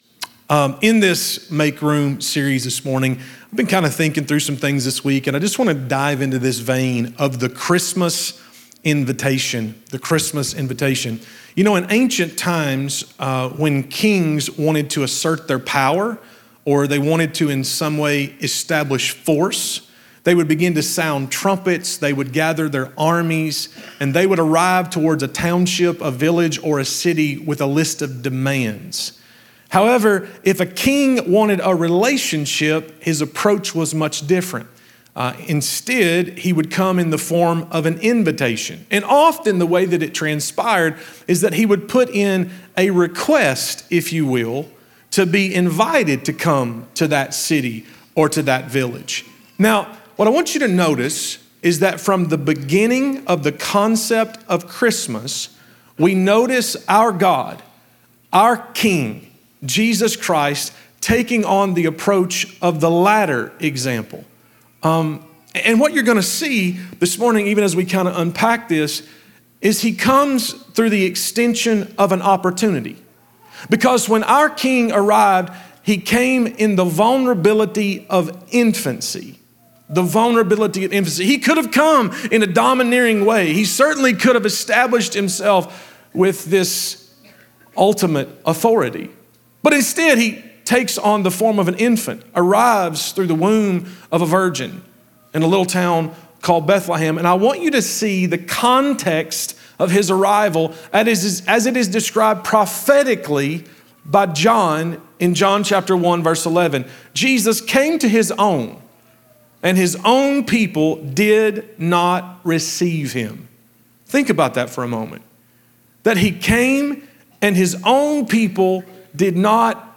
Christmas isn’t about God overpowering humanity—it’s about God inviting us into relationship. In this message from the Make Room series, we explore the Christmas invitation, the vulnerability of Jesus, and what it means to truly make room for Him in every part of our lives.